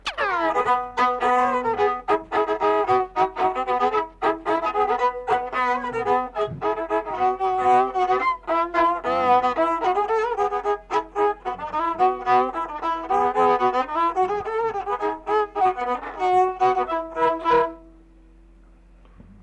Pasakojimas
Erdvinė aprėptis Luokė Pigonys Ausieniškės
Atlikimo pubūdis vokalinis